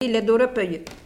Mots Clé vêtement(s), habit(s) ; Localisation Saint-Maixent-de-Beugné
Catégorie Locution